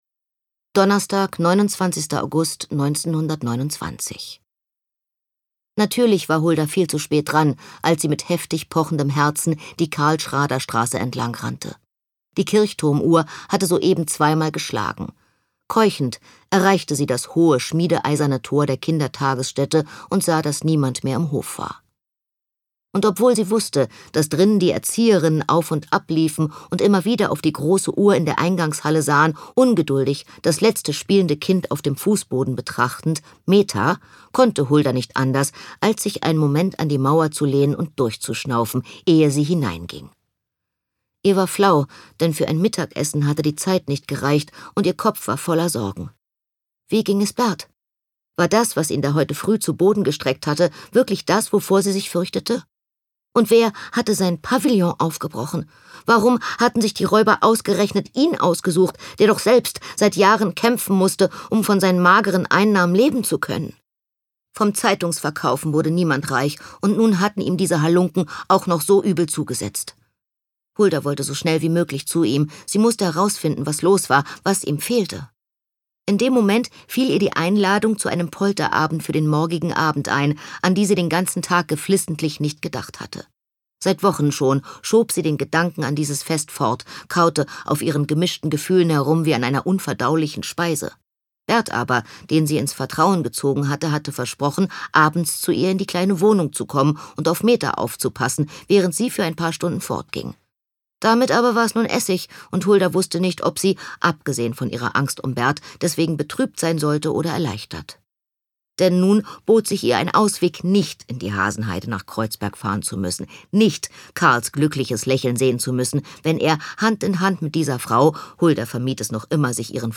Fräulein Gold: Die Lichter der Stadt Anne Stern (Autor) Anna Thalbach (Sprecher) Audio Disc 2023 | 1.